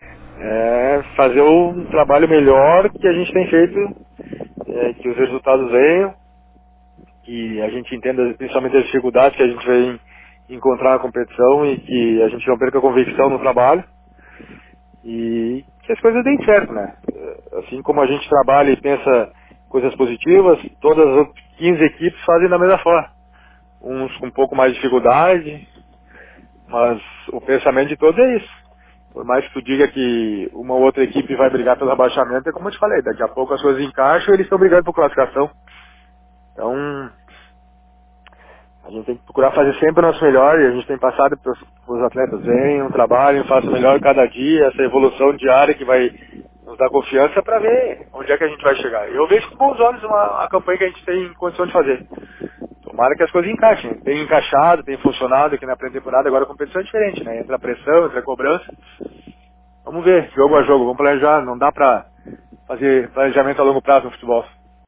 EntrevistaEsportes Autor: - 1 de março de 2018